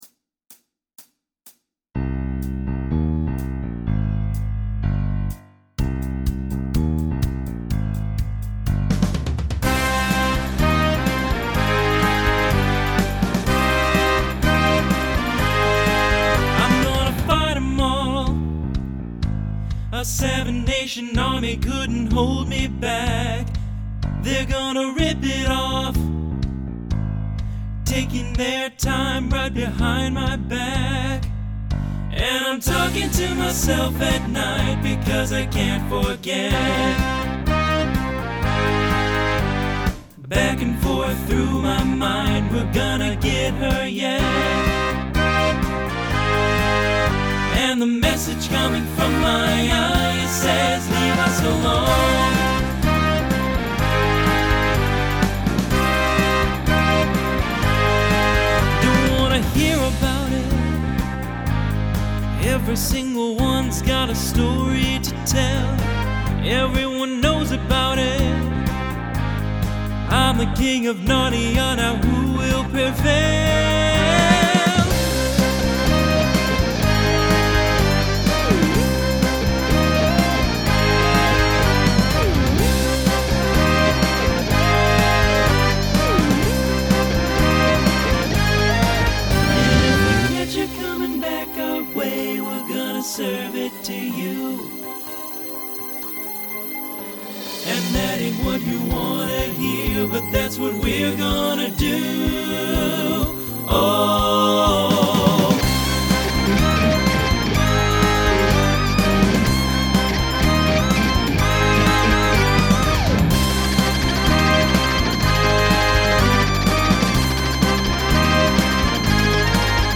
Genre Rock Instrumental combo
Mid-tempo , Transition Voicing TTB